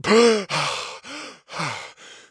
gasp1.mp3